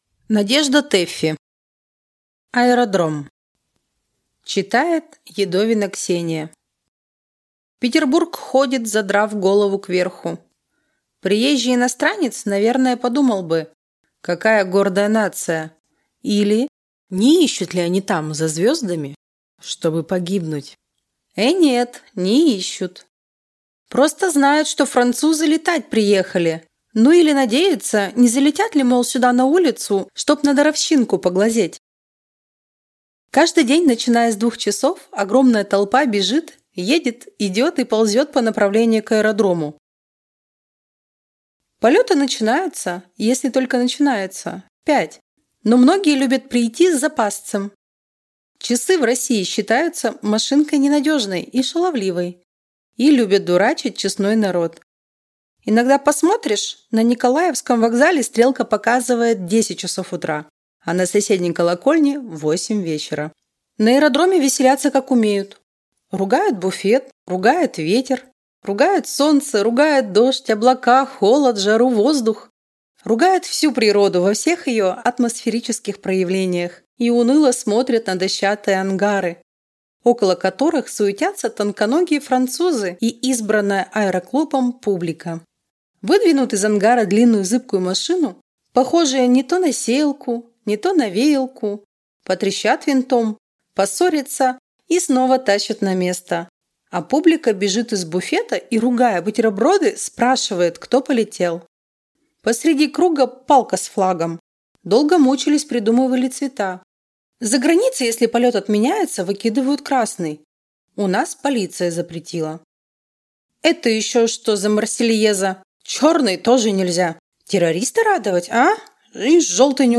Аудиокнига Аэродром | Библиотека аудиокниг